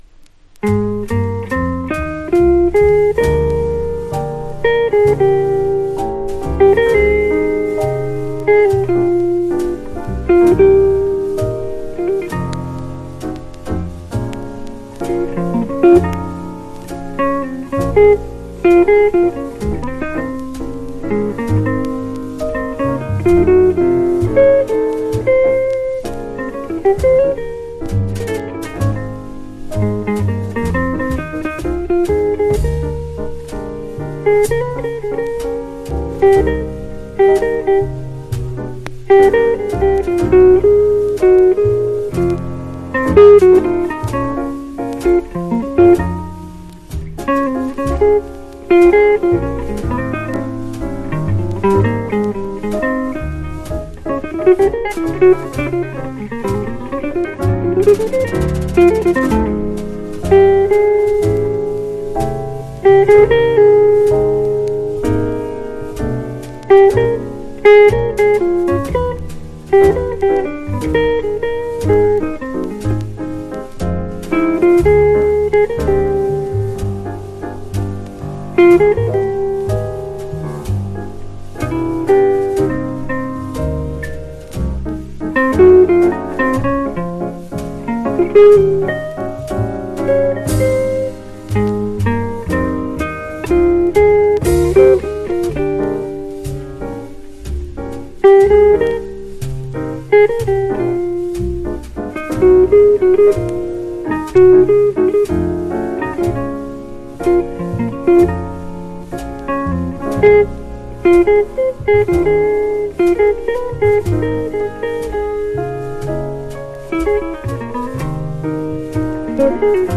（プレスによりチリ、プチ音ある曲あり）（B-2 くもり少しありますが音に影響なし）
Genre EURO JAZZ